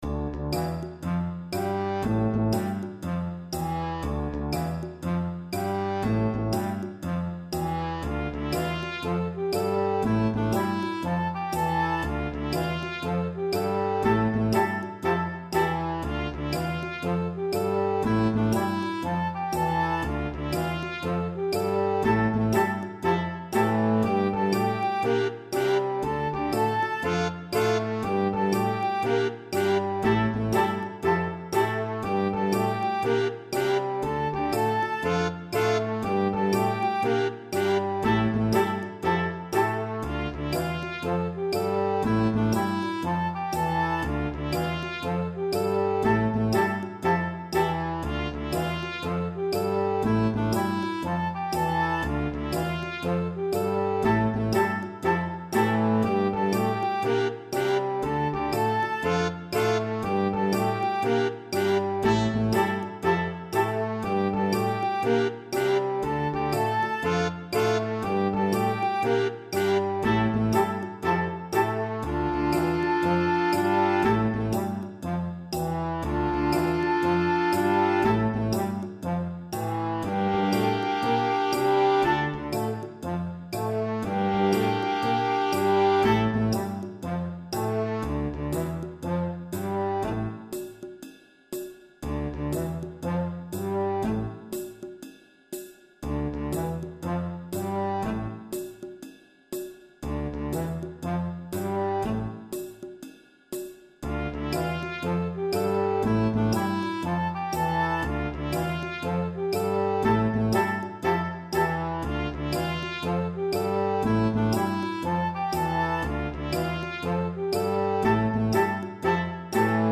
Un petit swing